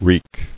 [reek]